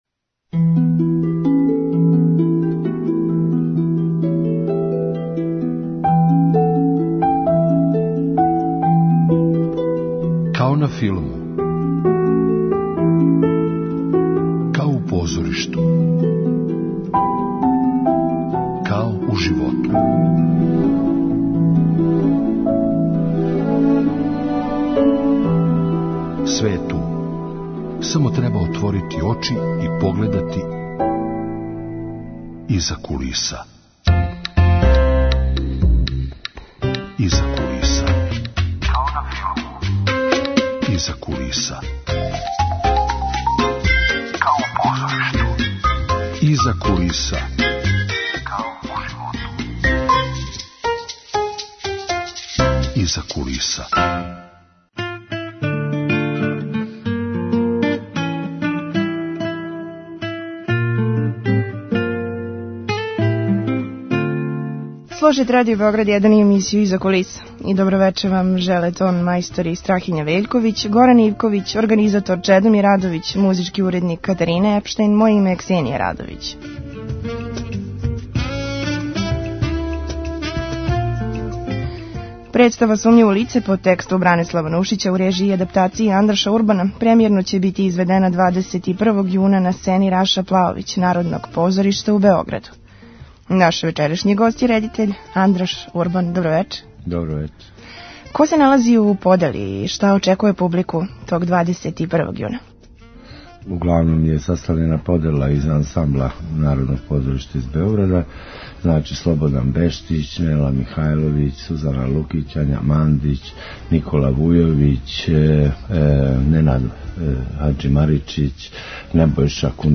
Гост : редитељ